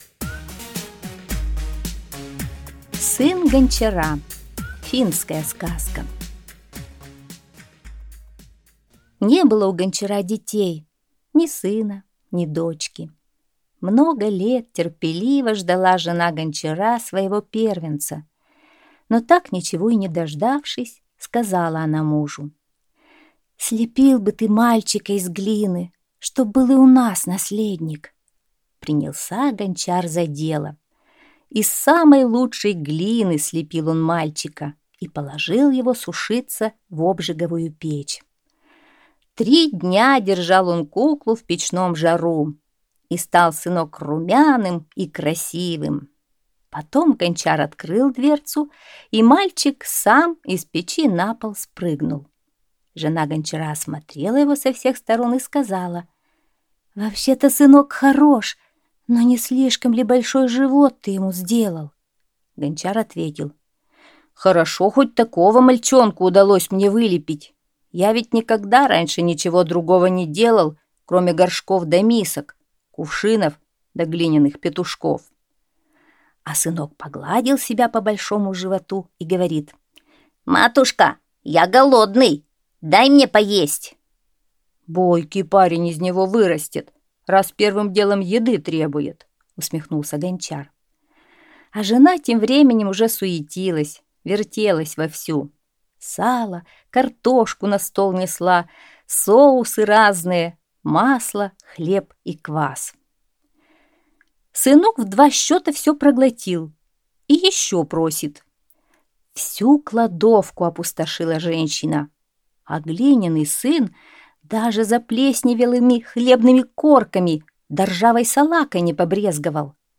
Аудиосказка «Сын гончара»